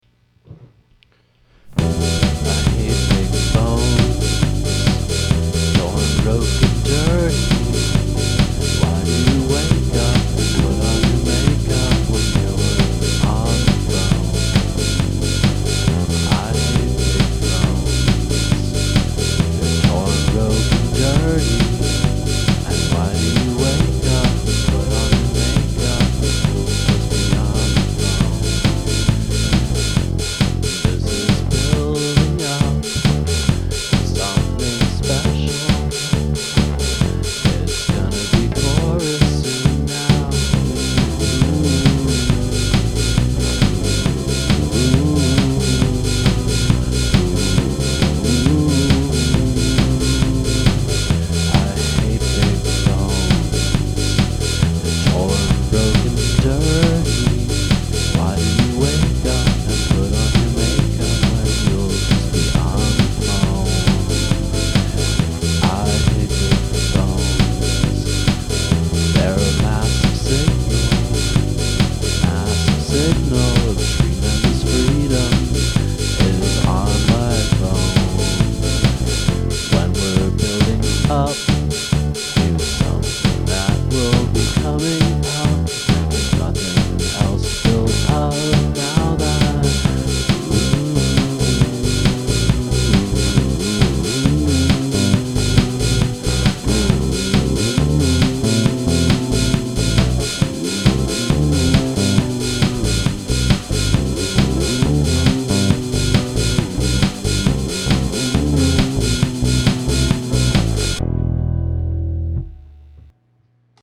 Oh no, it's a disco beat!
It's at 135 bpm right now - do you think a drop to around 120-125 would be better?